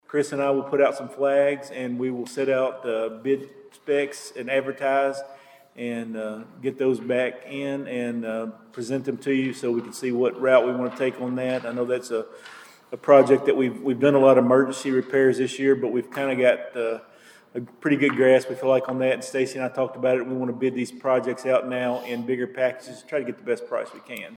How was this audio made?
During Monday night’s meeting, the Princeton City Council moved forward with plans for sidewalk repairs, received updates on the winter storm’s impact, and learned that a longtime city employee is stepping down.